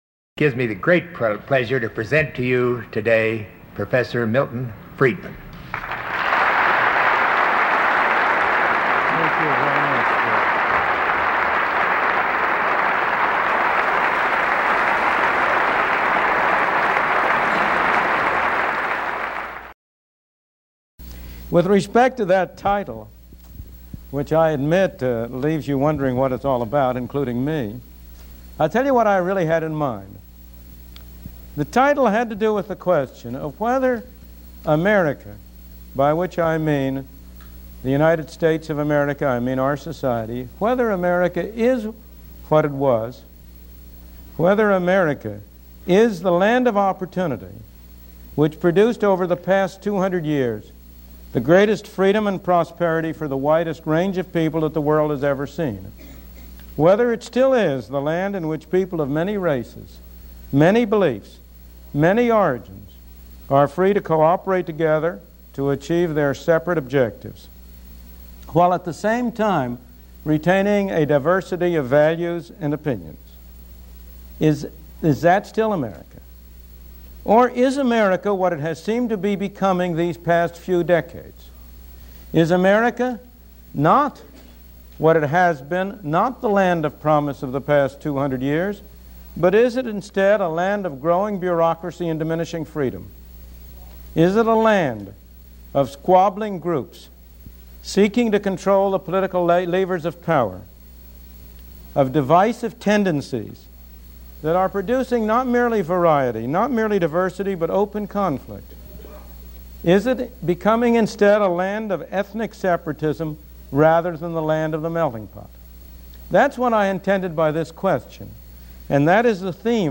Episode 1 - Milton Friedman Speaks - What Is America?